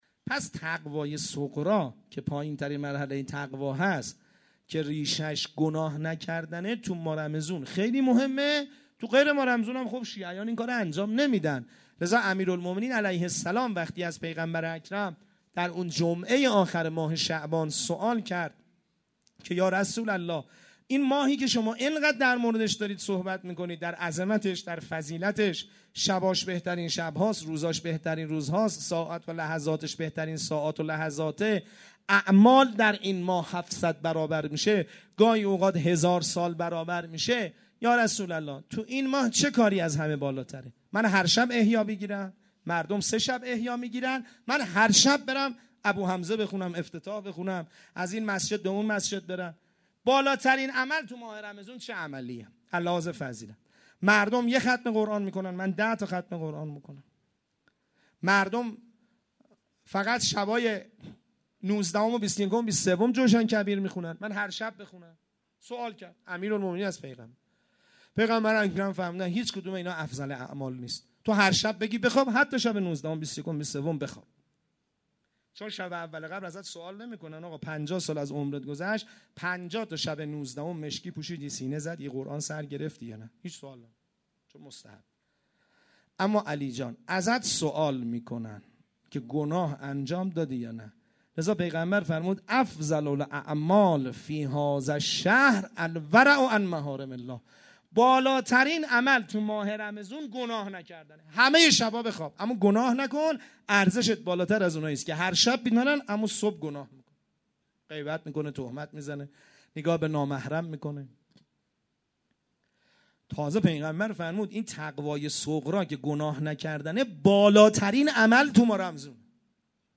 مراسم مناجات شب بیست و دوم رمضان سه شنبه 8 مرداد 1392 > سخنرانی > 1- بخش اول